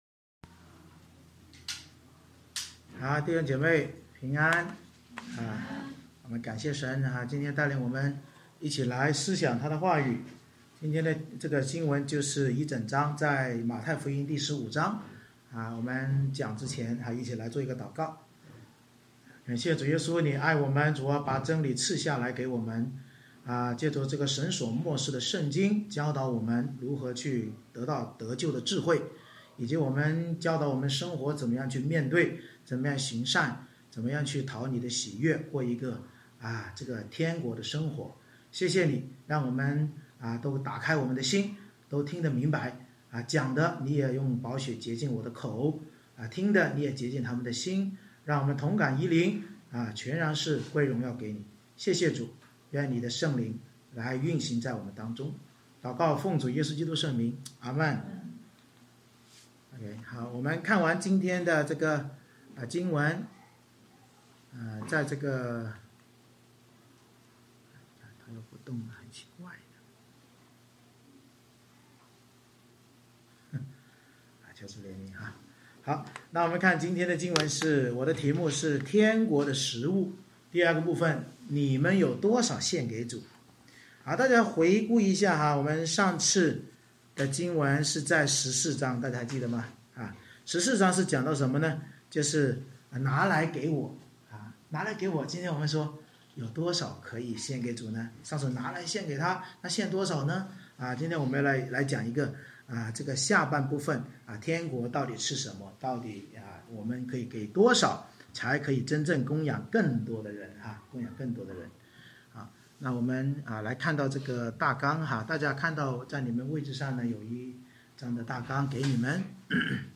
《马太福音》讲道系列 Passage: 马太福音15章 Service Type: 主日崇拜 面对宗教领袖污秽人的遗传教导和迎南妇人对福音渴慕的信心，耶稣询问门徒有多少饼献上，警醒我们要弃绝传统束缚，为了福音使命而献上所有。